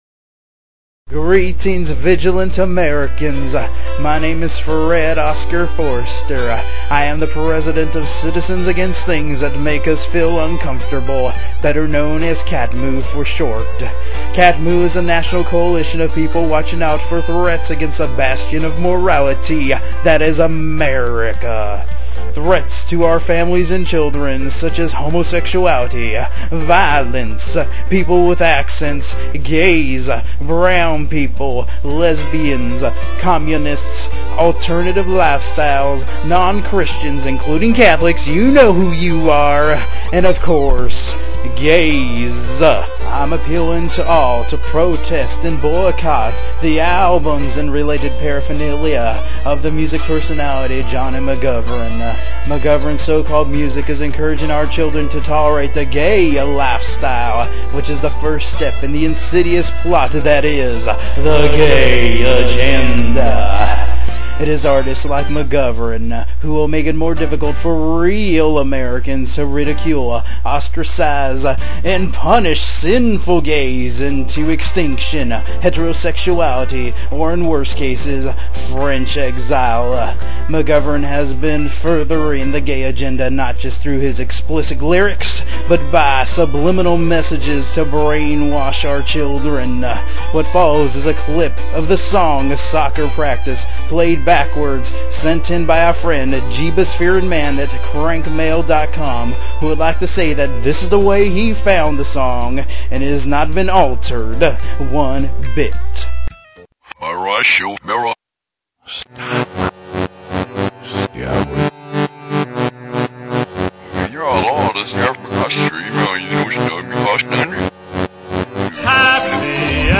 I've posted another series of short audio sketches
using Audacity Freeware I had to scale back on the sound quality to get it on here